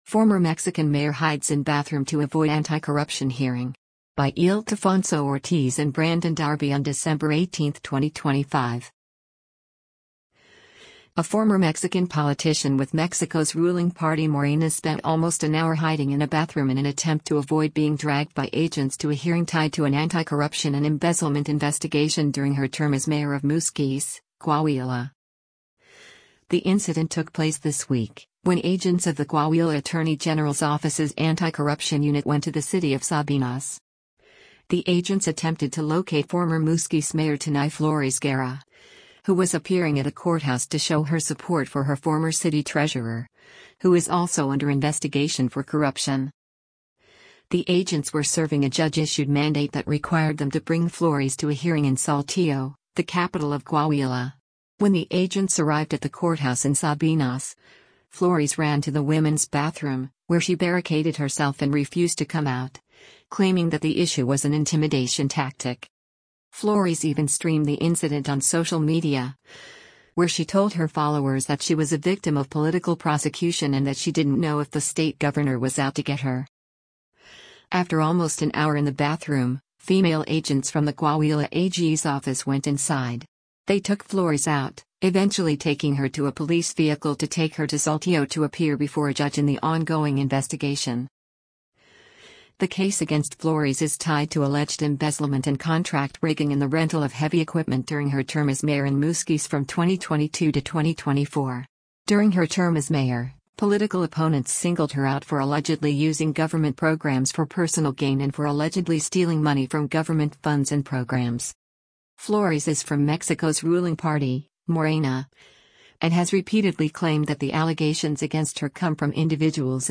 Flores even streamed the incident on social media, where she told her followers that she was a victim of political prosecution and that she didn’t know if the state governor was out to get her.